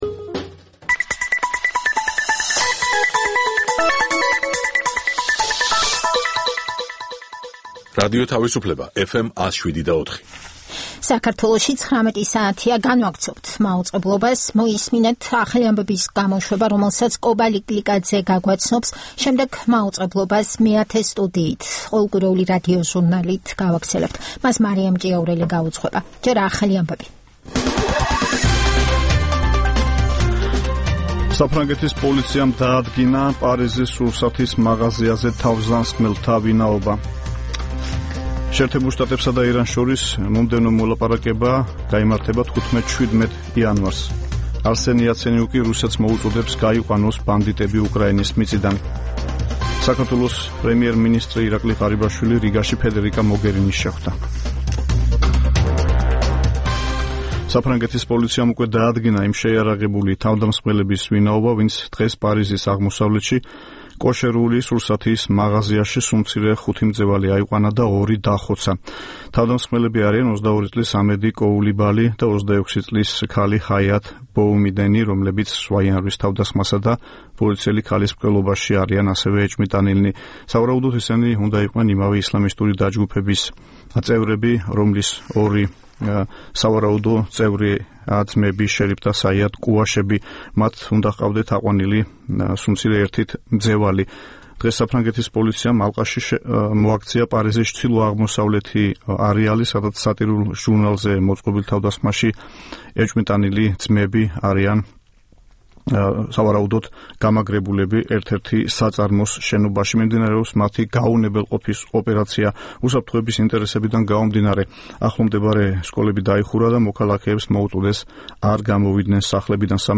ეს პროგრამა ჩვენი ტრადიციული რადიოჟურნალია, რომელიც ორი ათეული წლის წინათ შეიქმნა ჯერ კიდევ მიუნხენში - რადიო თავისუფლების ყოფილ შტაბ-ბინაში, სადაც ქართული რედაქციის გადაცემების ჩასაწერად მე-10 სტუდია იყო გამოყოფილი. რადიოჟურნალი „მეათე სტუდია“ მრავალფეროვან თემებს ეძღვნება - სიუჟეტებს პოლიტიკასა და ეკონომიკაზე, გამოფენებსა და ფესტივალებზე, ინტერვიუებს ქართველ და უცხოელ ექსპერტებთან და ხელოვანებთან.